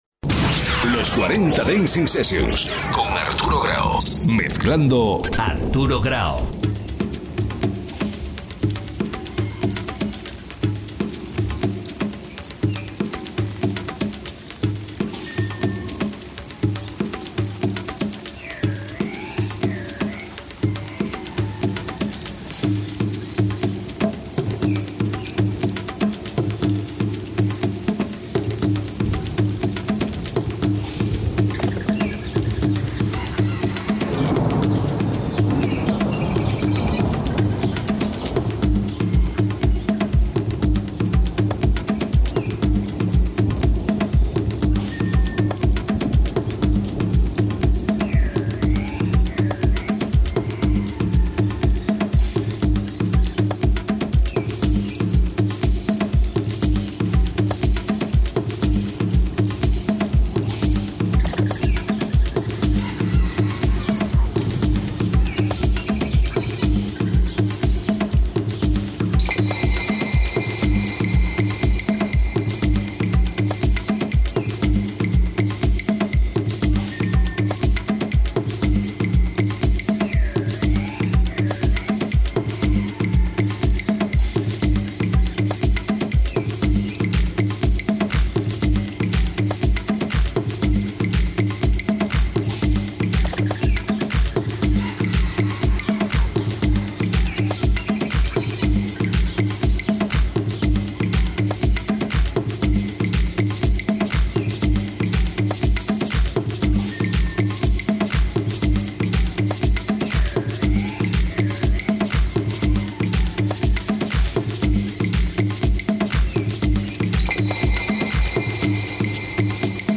House Classics.